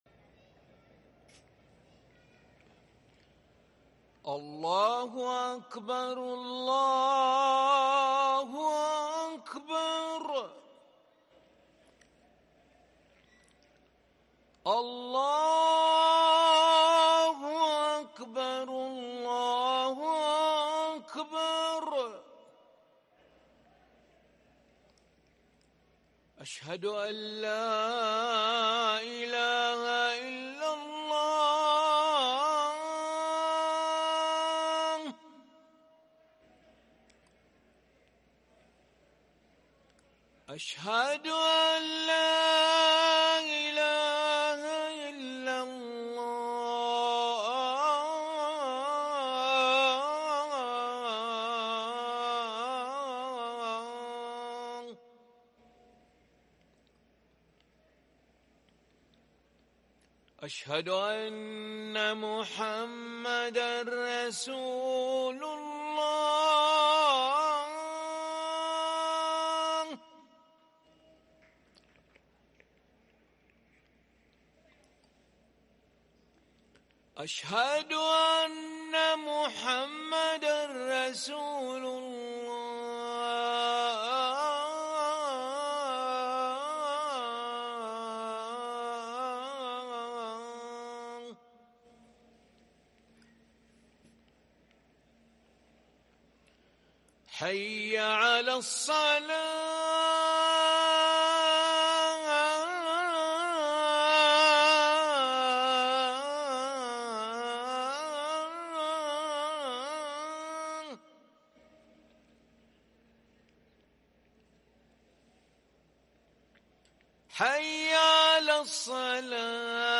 أذان العشاء للمؤذن علي ملا الأحد 29 صفر 1444هـ > ١٤٤٤ 🕋 > ركن الأذان 🕋 > المزيد - تلاوات الحرمين